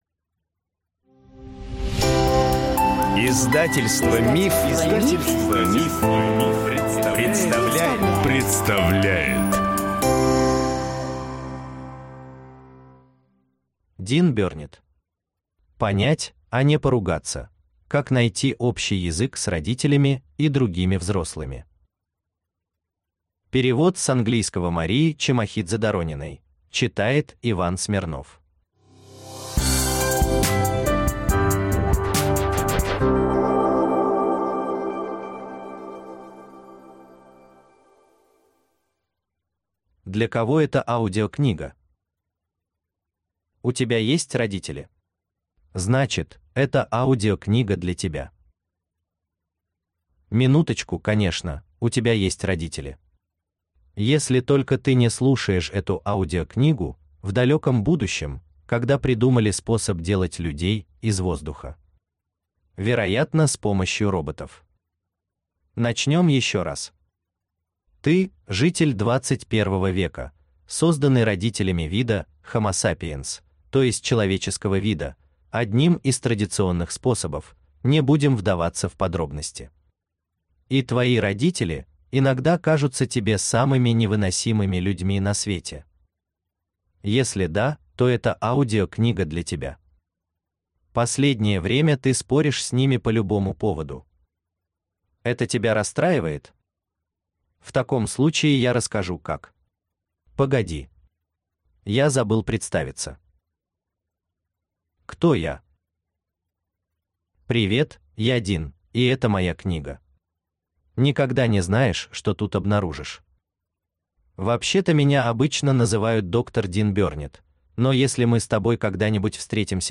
Аудиокнига Понять, а не поругаться. Как найти общий язык с родителями и другими взрослыми | Библиотека аудиокниг